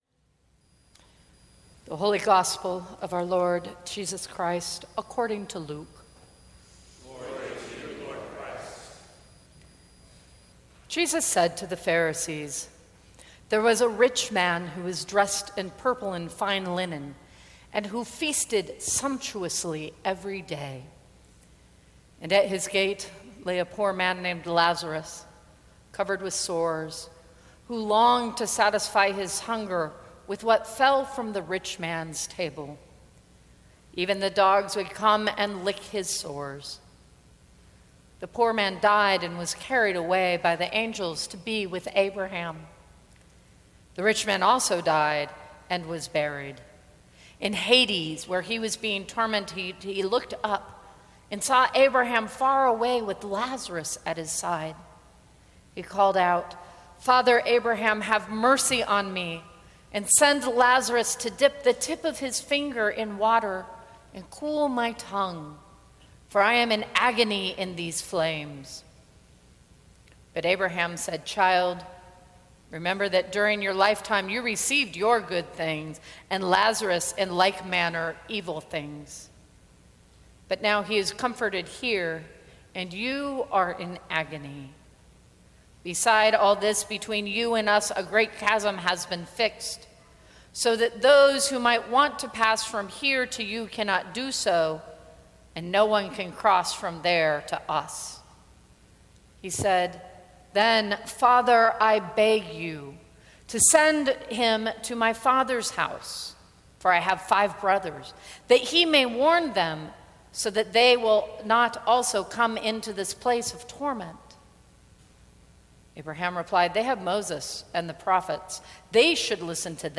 Sermons from St. Cross Episcopal Church The Nature of Mercy Sep 25 2016 | 00:16:13 1x 00:00 / 00:16:13 Subscribe Share Apple Podcasts Spotify Overcast RSS Feed Share Link Embed